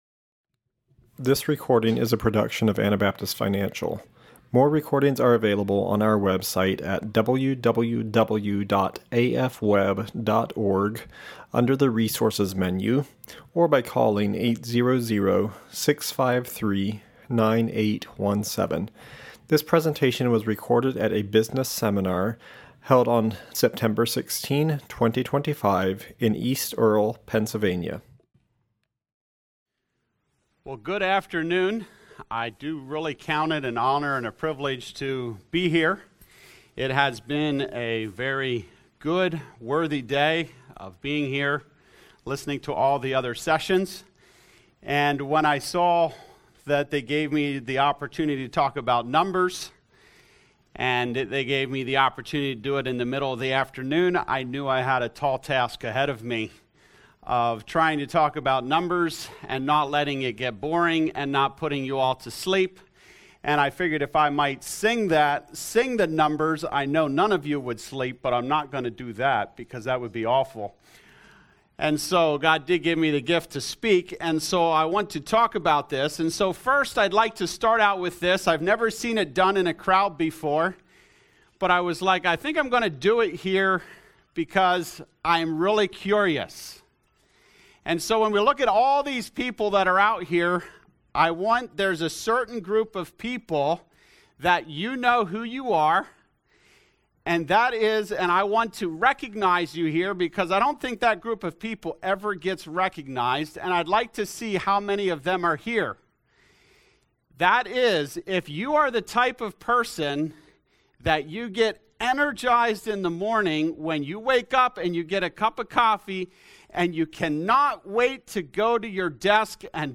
Pennsylvania Business Seminar 2025